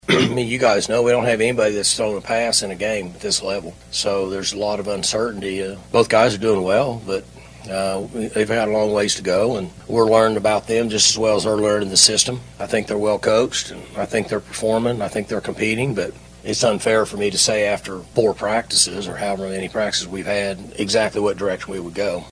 Oklahoma State head football coach Mike Gundy met with the media on Saturday for the first time since the Cowboy’s fall camp began.